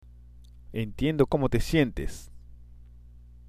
（エンティエンド　コモテ　シエンテス）